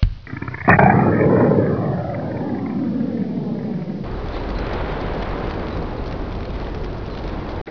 Bigboom
BIGBOOM.WAV